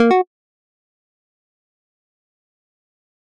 システム系ゲーム風効果音第26弾！よくあるSEっぽい効果音です！